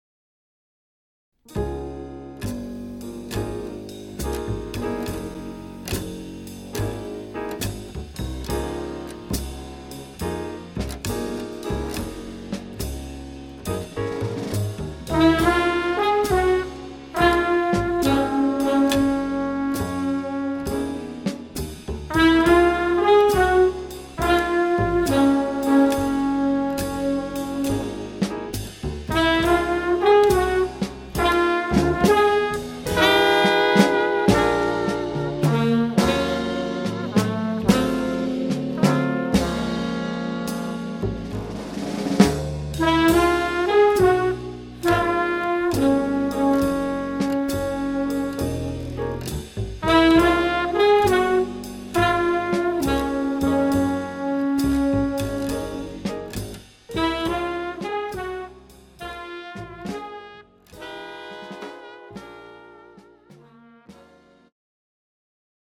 The Best In British Jazz
Recorded at Red Gables Studio.